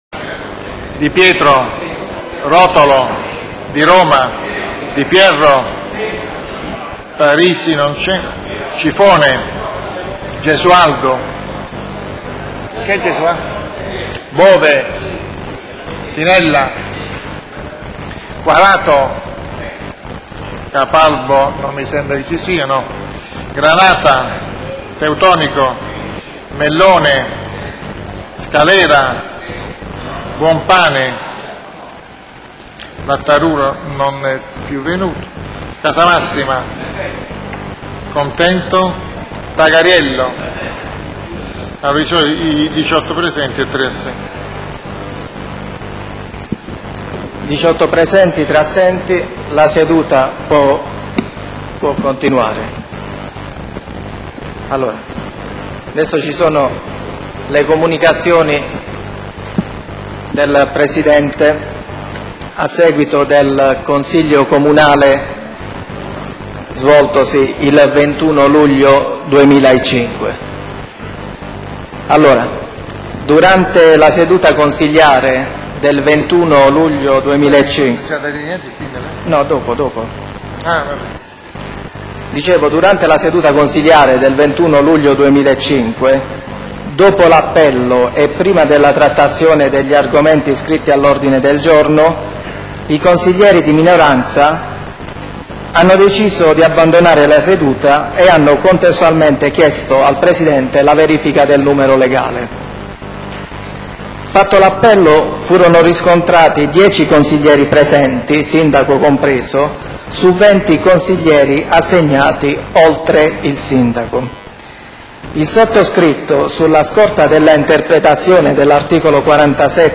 CLICCA QUI PER ASCOLTARE IL CONSIGLIO COMUNALE INTEGRALMENTE